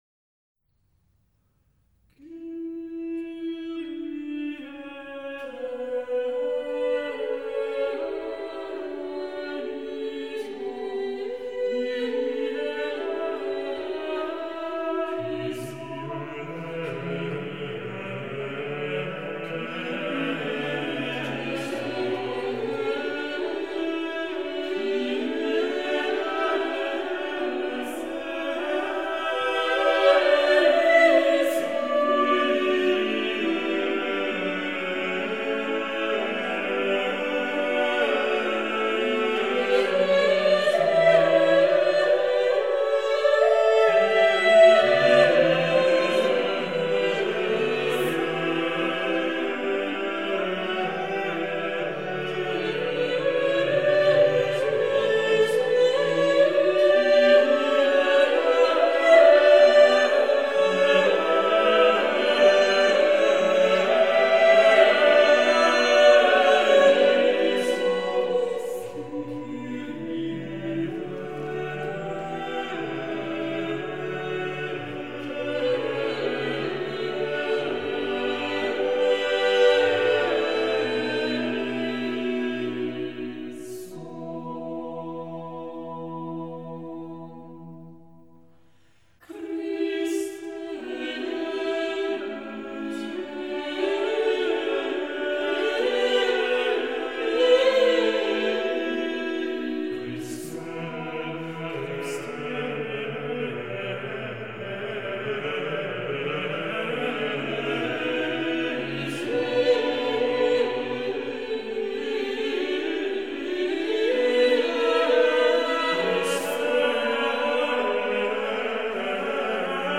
note: afin de ménager la bande passante, les fichiers en écoute immédiate sont encodés en mono, 22 KHz, 32 Kbps, ... ce qui signifie que leur qualité n'est pas "excellentissime"!
Messe à 4 voix
enregistré par Philippe Herreweghe
Monteverdi_Messa_a_4_voci_da_cappella-1_Kyrie.mp3